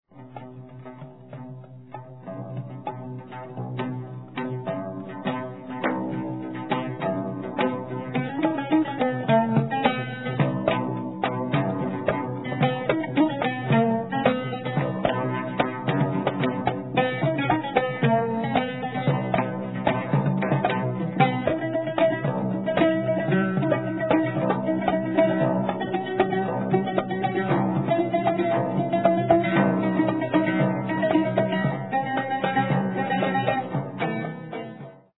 traditional Near Eastern style
Oud and Drum